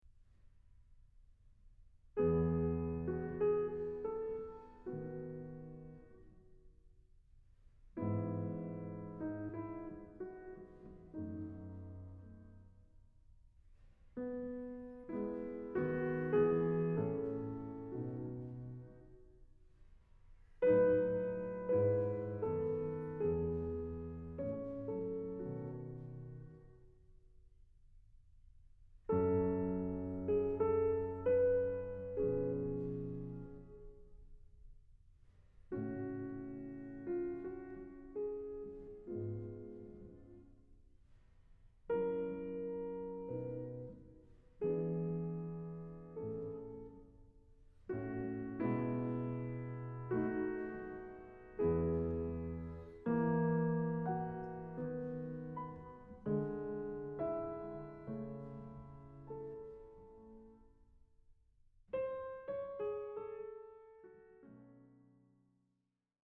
This movement has a lot of theatrical drama. It starts like an aria, with those silences that Beethoven are so fond of.